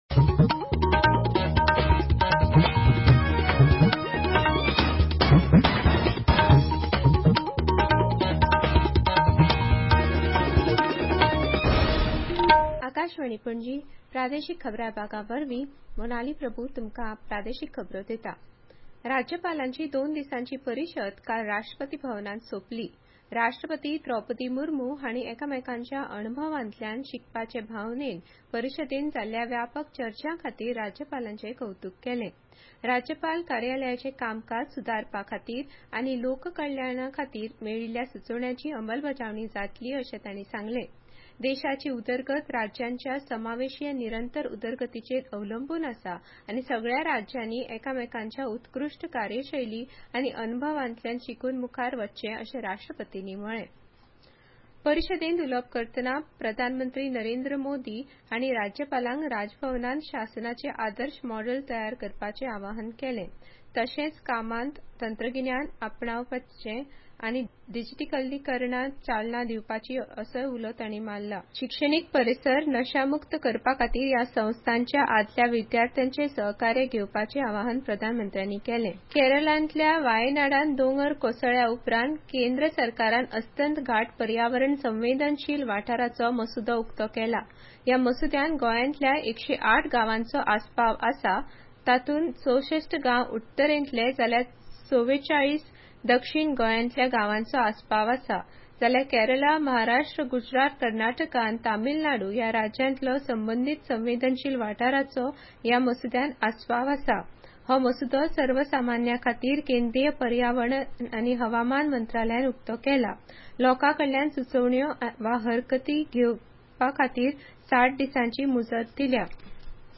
Access Bulletins From Cities